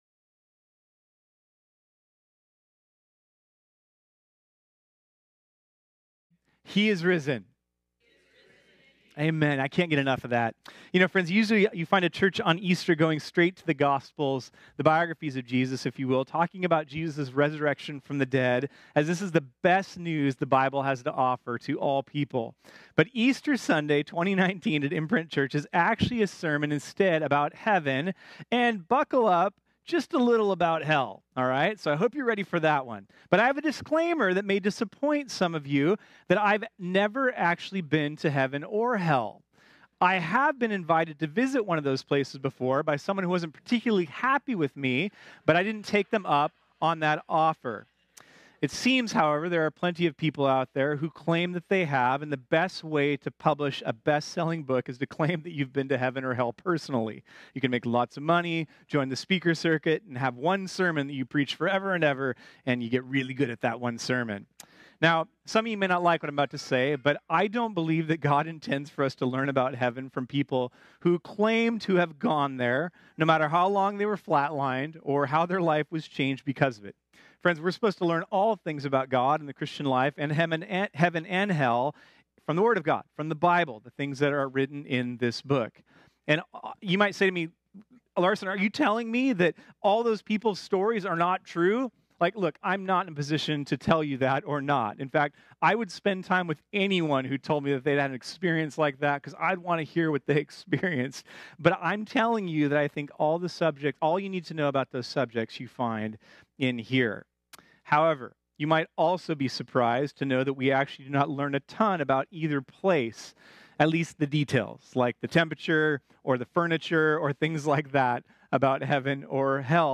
This sermon was originally preached on Sunday, April 21, 2019.